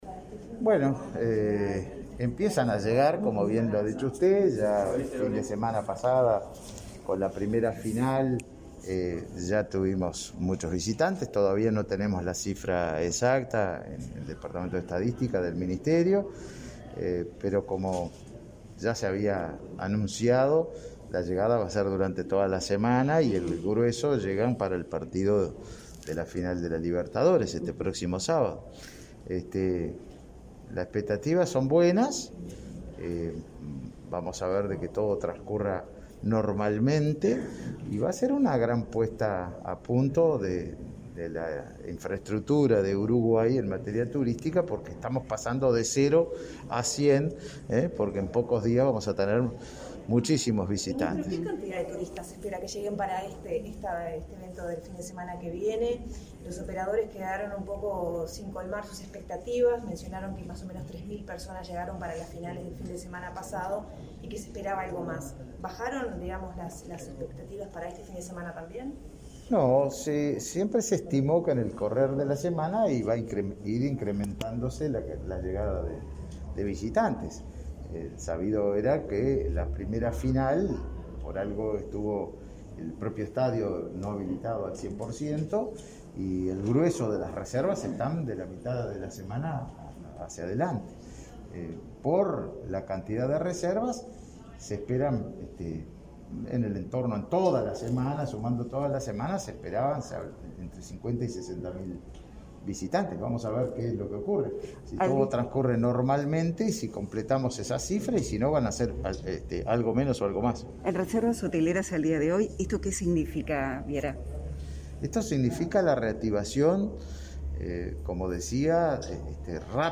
Declaraciones a la prensa del ministro de Turismo, Tabaré Viera